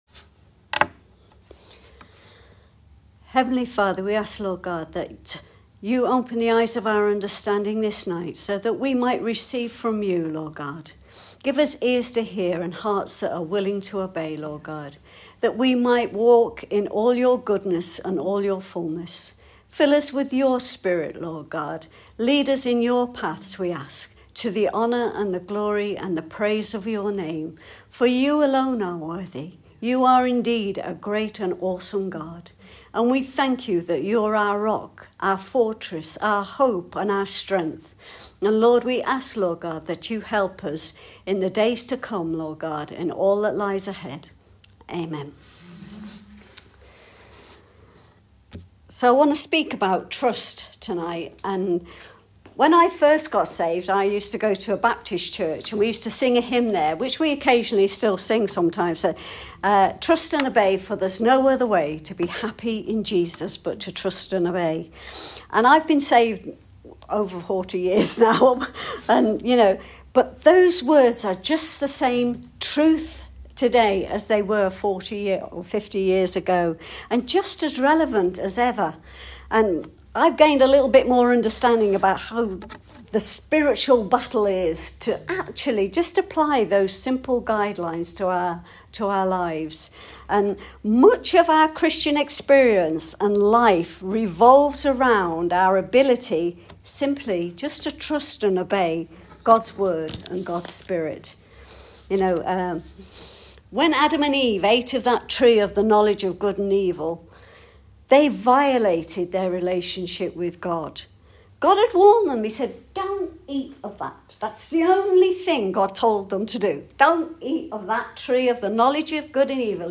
Ladies message